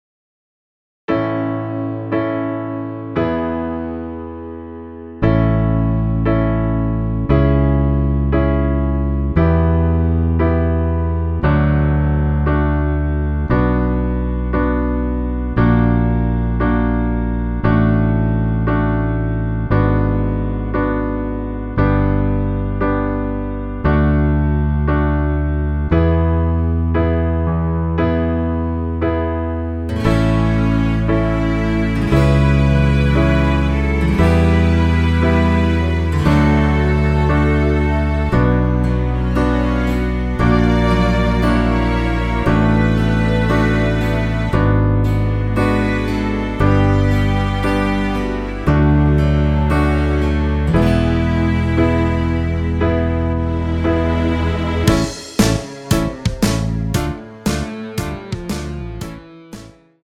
전주 없이 시작 하는 곡이라 노래 하시기 편하게 전주 2마디 만들어 놓았습니다.
앞부분30초, 뒷부분30초씩 편집해서 올려 드리고 있습니다.
중간에 음이 끈어지고 다시 나오는 이유는